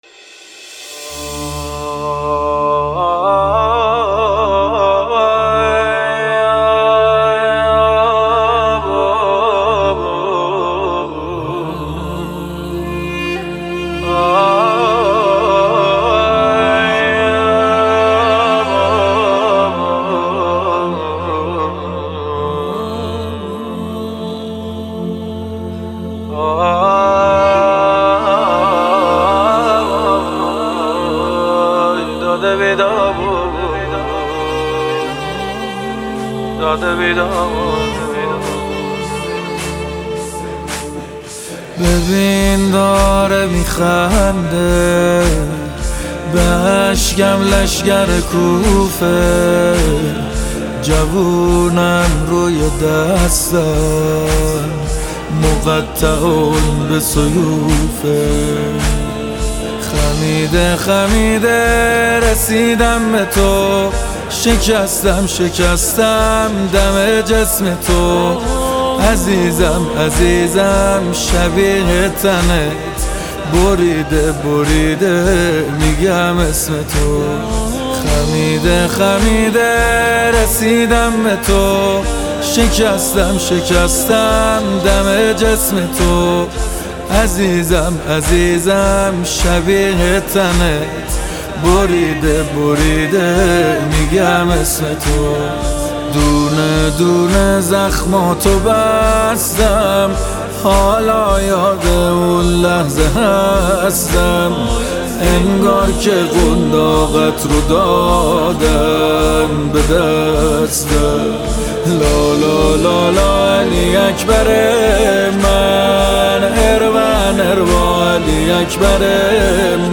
نماهنگ مذهبی جدید
نماهنگ ویژه شب هشتم محرم